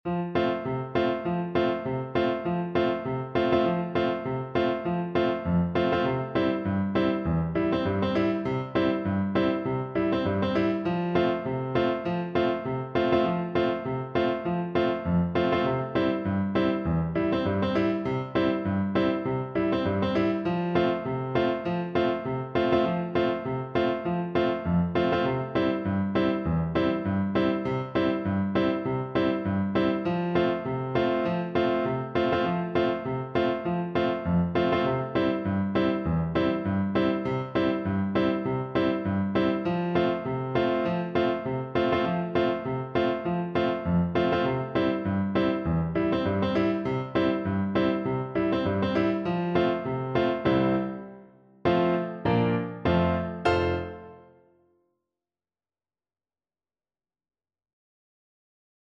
Play (or use space bar on your keyboard) Pause Music Playalong - Piano Accompaniment Playalong Band Accompaniment not yet available transpose reset tempo print settings full screen
Two in a bar with a light swing =c.100
Traditional (View more Traditional Flute Music)